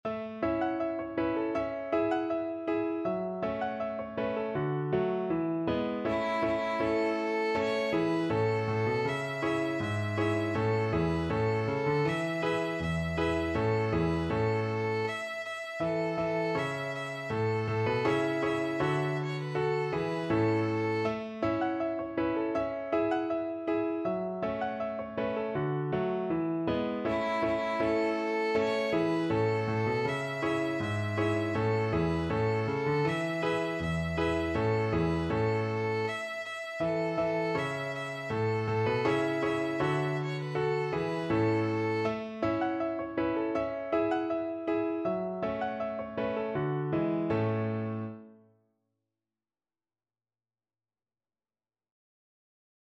Traditional Trad. Ba luobo (Pull the Carrots) Violin version
Violin
2/4 (View more 2/4 Music)
A major (Sounding Pitch) (View more A major Music for Violin )
Steadily =c.80
Traditional (View more Traditional Violin Music)
Chinese